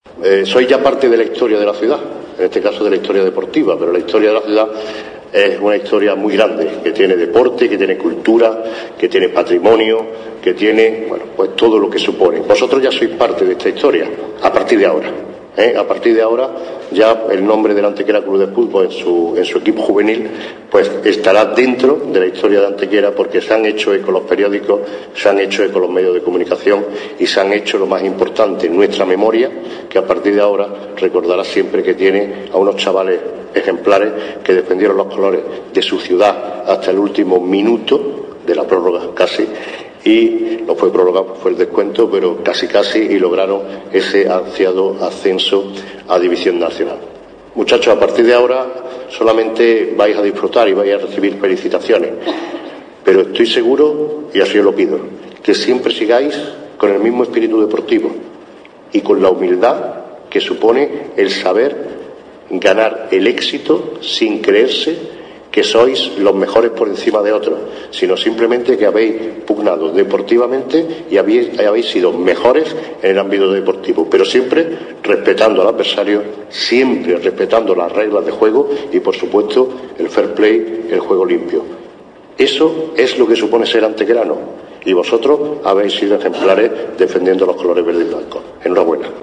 Fotografías de la recepción oficial que el alcalde de Antequera, Manolo Barón, y el teniente de alcalde de Educación y Deportes, Alberto Vallespín, ofrecieron en la tarde de ayer en el Salón de Plenos del Ayuntamiento a los jugadores y cuerpo técnico del equipo juvenil preferente del Antequera Club de Fútbol.
Cortes de voz